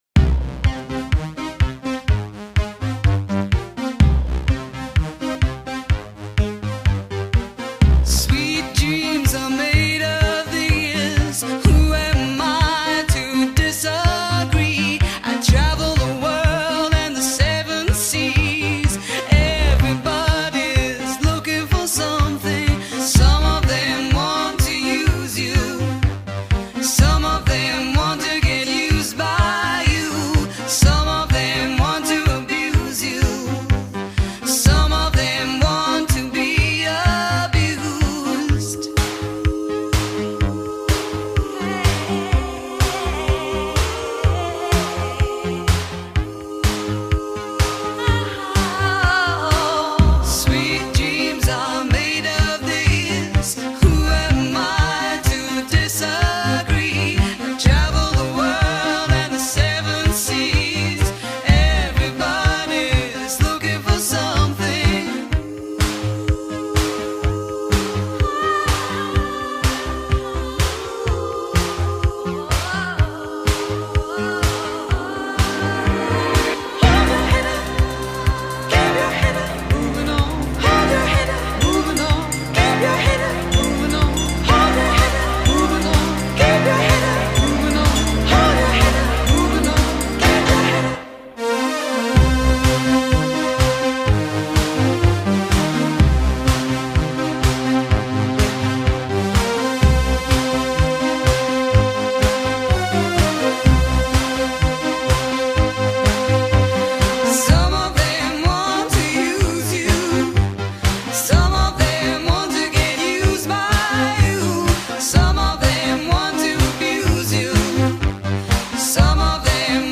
BPM125-132
Audio QualityCut From Video